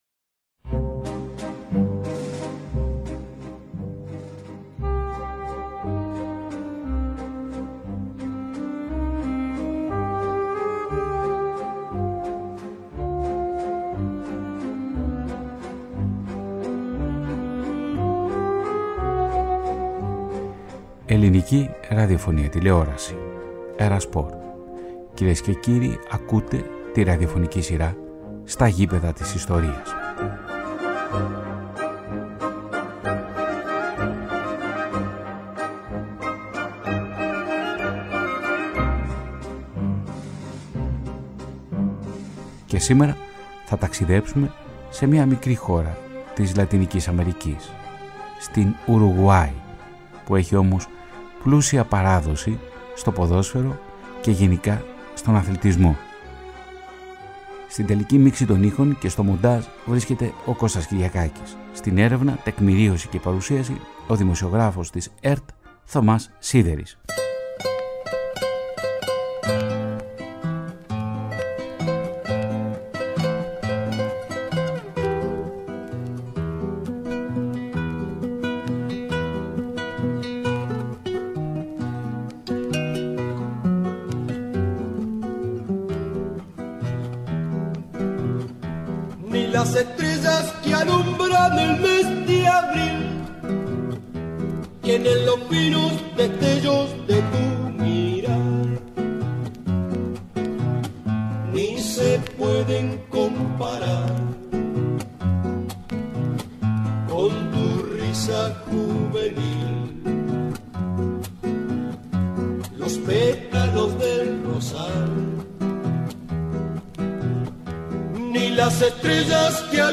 Η ραδιοφωνική σειρά “Στα γήπεδα της Ιστορίας” ταξιδεύει σε μία μικρή χώρα της Λατινικής Αμερικής, την Ουρουγουάη, το πρώτο μισό του 20ού αιώνα.